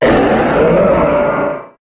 Cri de Méga-Camérupt dans Pokémon HOME.
Cri_0323_Méga_HOME.ogg